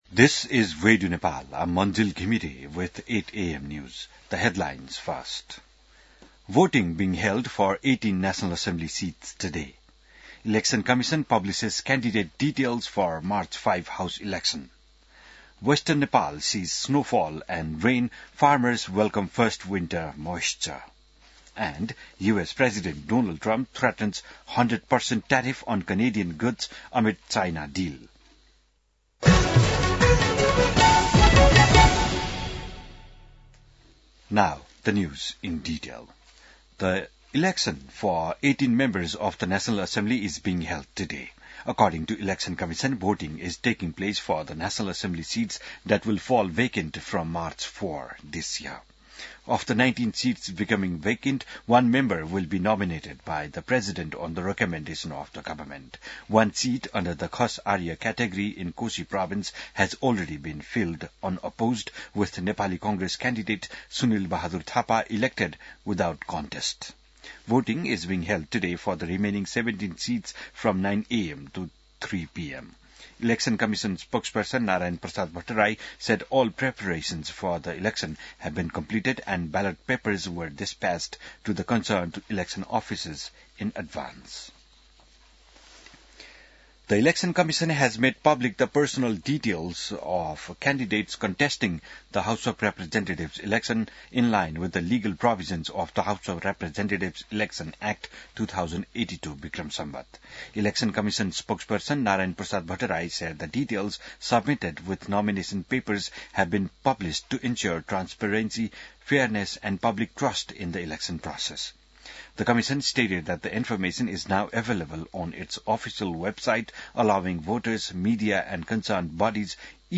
बिहान ८ बजेको अङ्ग्रेजी समाचार : ११ माघ , २०८२